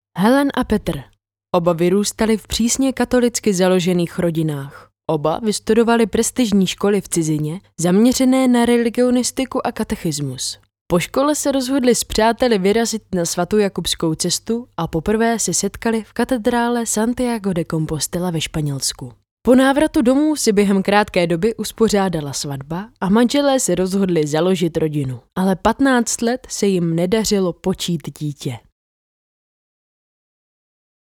Ženský voiceover do reklamy / 90 sekund
Hledáte do svého videa příjemný ženský hlas?
Nahrávání probíhá v profesionálním studiu a výsledkem je masterovaná audio stopa ve formátu WAV, ořezaná o nádechy a další rušivé zvuky.